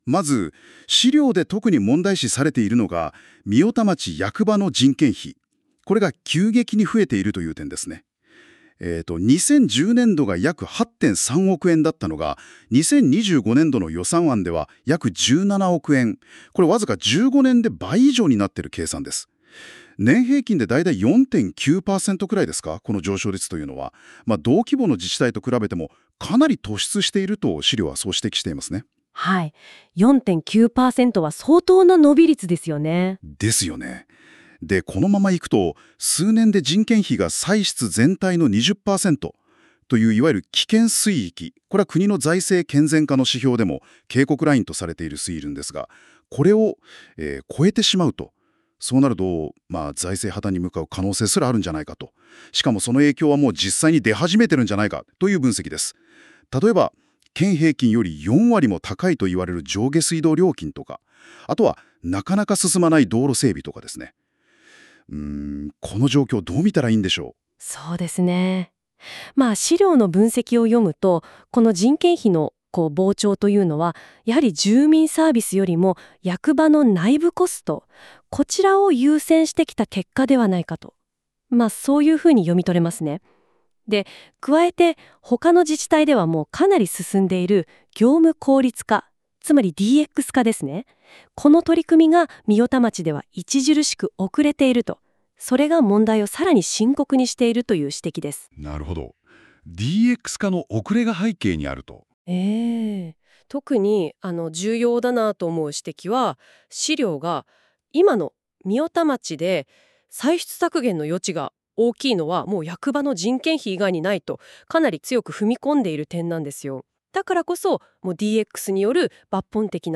音声解説
AI生成なので固有名詞などの誤読がありますがご了承ください。